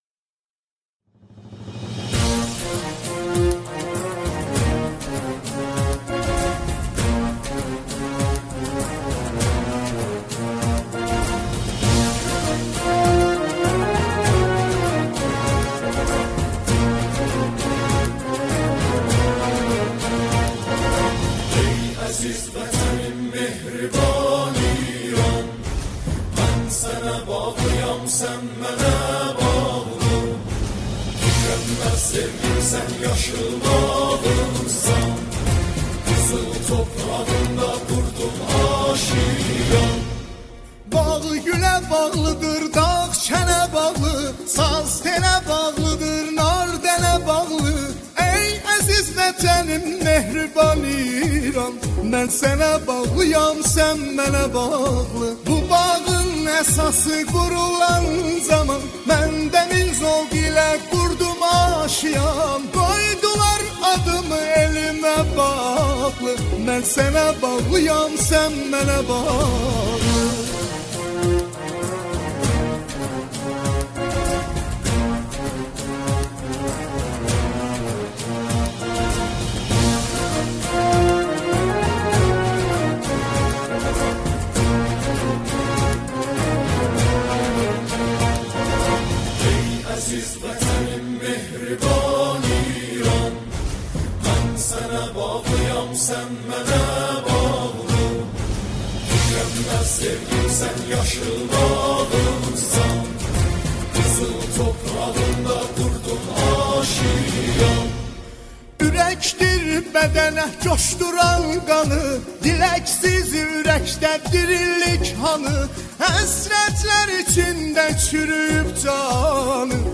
آنها در این قطعه، شعری را درباره کشور ایران همخوانی می‌کنند.